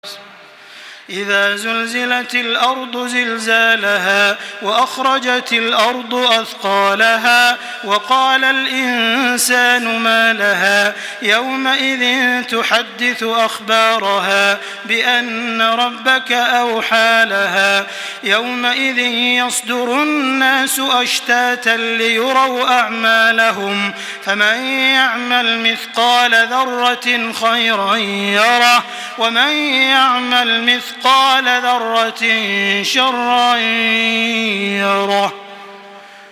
Surah Zelzele MP3 in the Voice of Makkah Taraweeh 1425 in Hafs Narration
Murattal